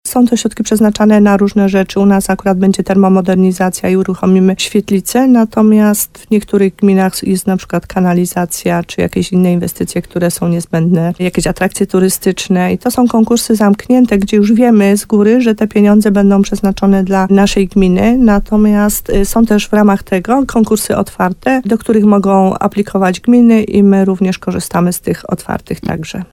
W programie Słowo za słowo w RDN Nowy Sącz, wójt Małgorzata Gromala podkreślała, że teraz te pieniądze zostaną podzielone pomiędzy gminy.